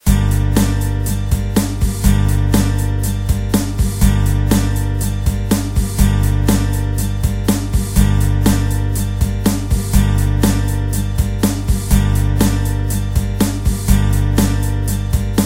Sempre serão duplas de MAIOR-MENOR ou então de MENOR-MAIOR com a mesma tônica e mesma levada.
maior-ou-menor-5.mp3